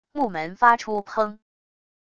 木门发出砰wav音频